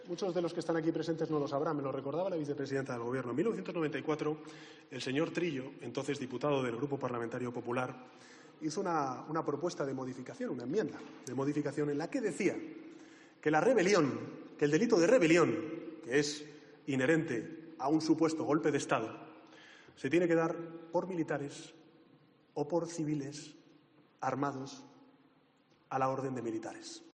Cinco meses después, este pasado miércoles 24 de octubre en la tribuna de oradores del Congreso de los Diputados, Sánchez contestaba a una pregunta del peneuvista Aitor Esteban con:  "como me recordaba la Vicepresidenta, en 1994 Federico Trillo hizo una propuesta de moficación en la que decía que el delito de rebelión, que es inherente a un supuesto golpe de Estado, se tiene que dar por militares o por civiles armados a las órdenes de militares".
Pedro Sánchez en el Congreso: El delito de rebelión se tiene que dar por militares